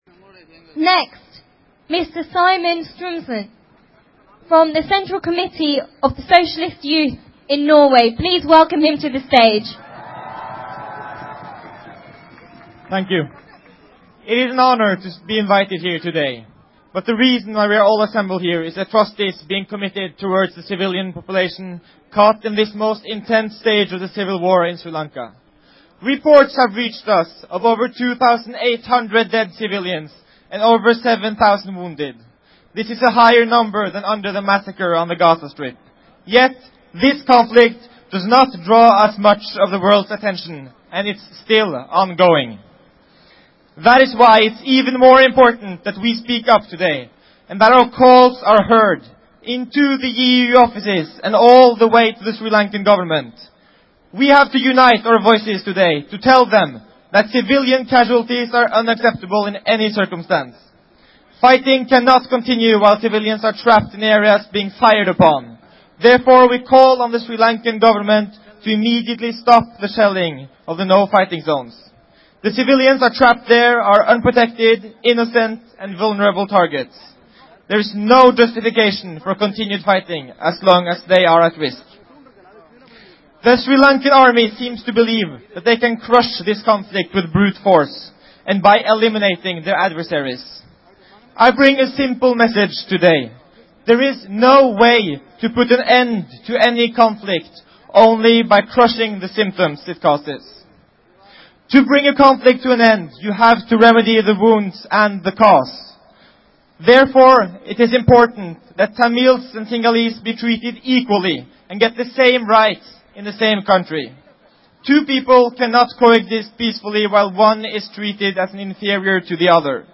Voice: Address